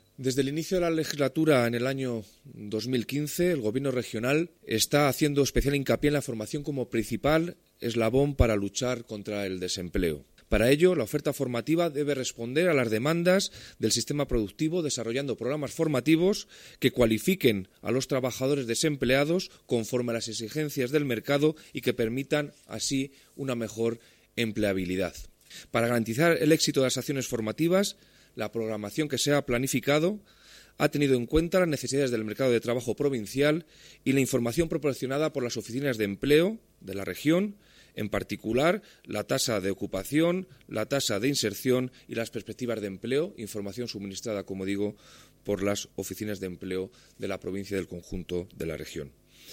El delegado de la Junta en Guadalajara, Alberto Rojo, habla de la influencia de la formación para el empleo en la mejora de la empleabilidad.